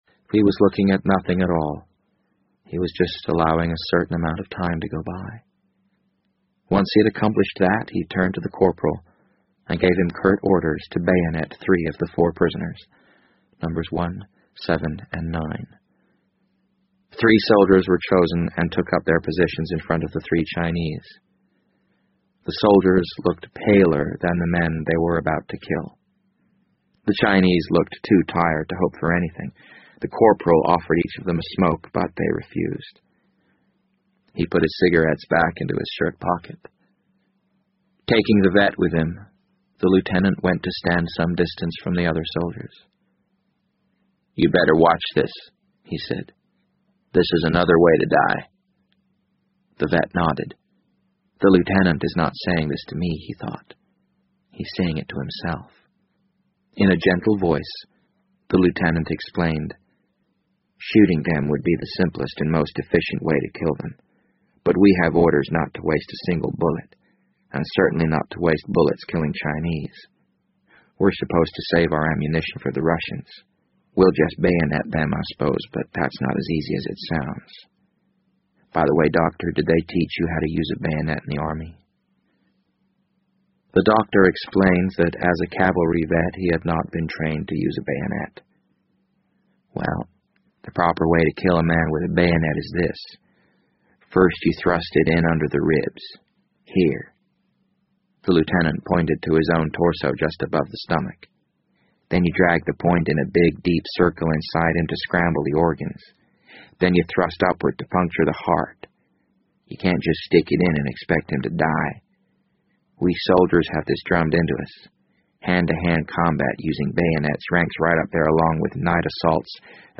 BBC英文广播剧在线听 The Wind Up Bird 013 - 9 听力文件下载—在线英语听力室